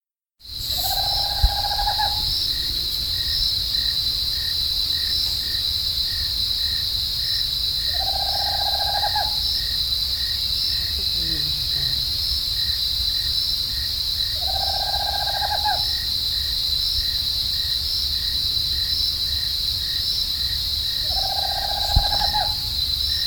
Alilicucú Común (Megascops choliba)
Nombre en inglés: Tropical Screech Owl
Fase de la vida: Adulto
Localidad o área protegida: Reserva El Bagual
Condición: Silvestre
Certeza: Vocalización Grabada